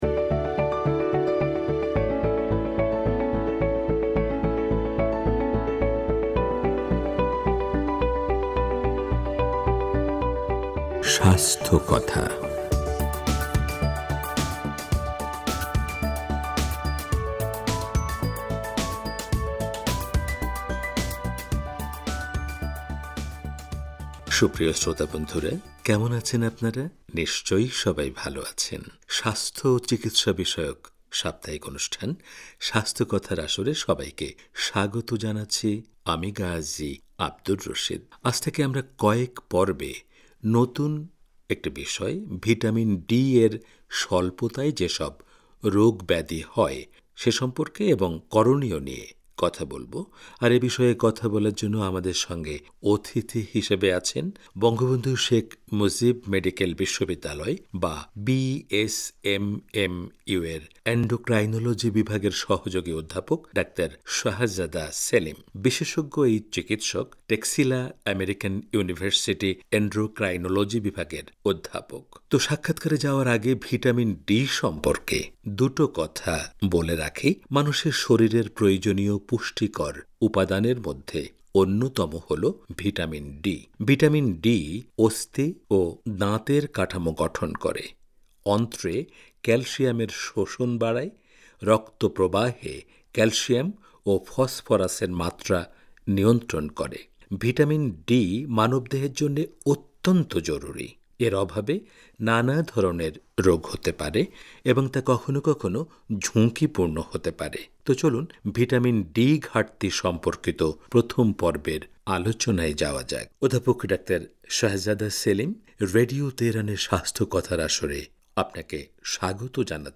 পুরো সাক্ষাৎকারটি তুলে ধরা হলো অনুষ্ঠানটির গ্রন্থনা
স্বাস্থ্য ও চিকিৎসা বিষয়ক সাপ্তাহিক অনুষ্ঠান